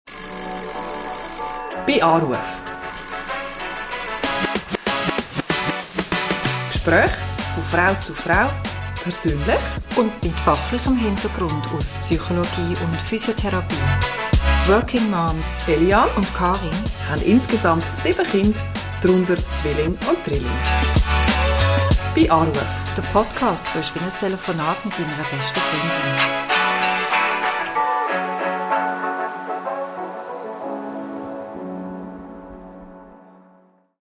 Trailer
Gespräche von Frau zu Frau, persönlich und mit fachlichem